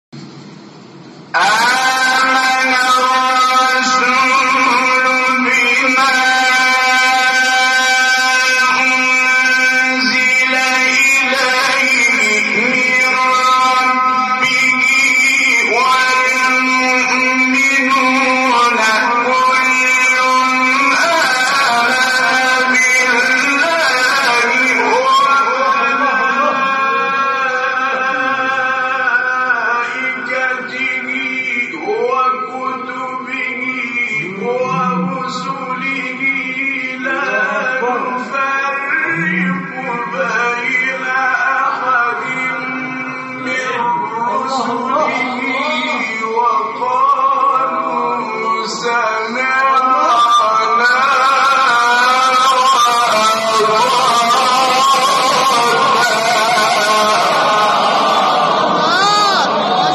مقام : صبا